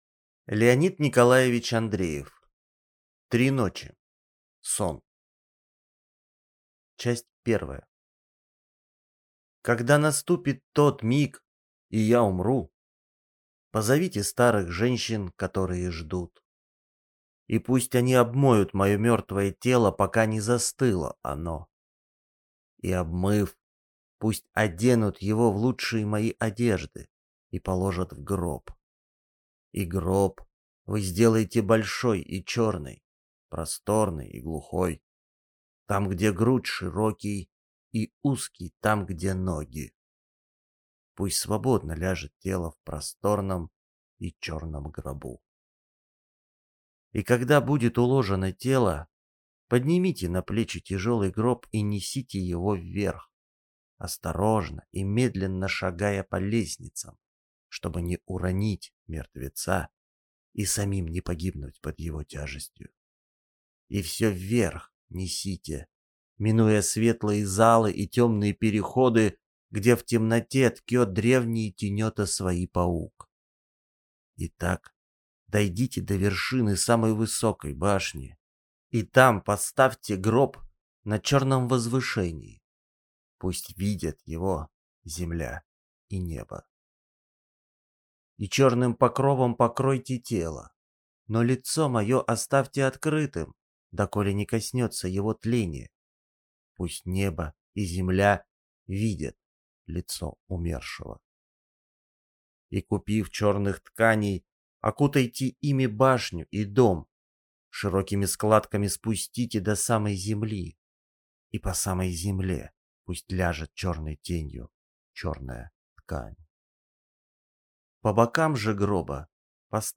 Аудиокнига Три ночи | Библиотека аудиокниг